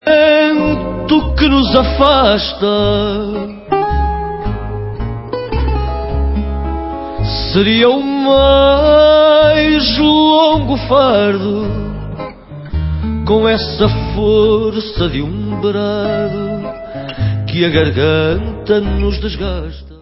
sledovat novinky v oddělení World/Fado